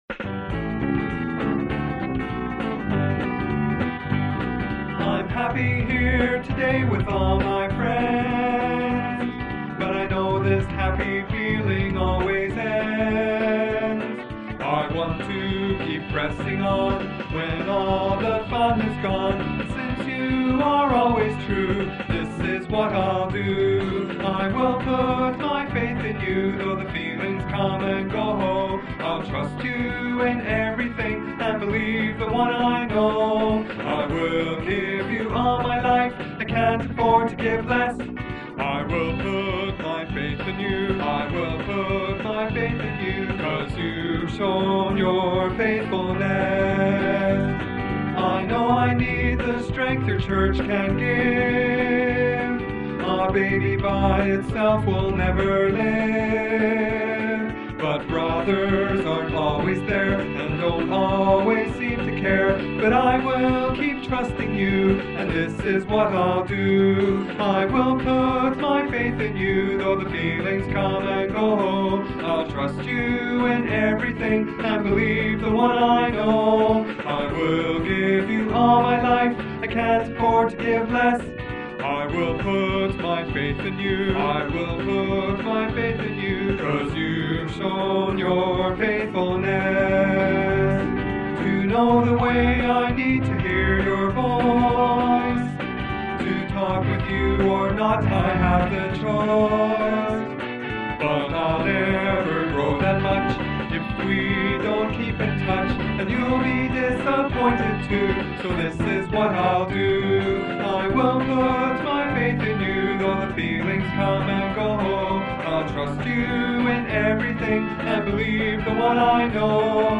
May 23: Volume boosted on all except Beaver.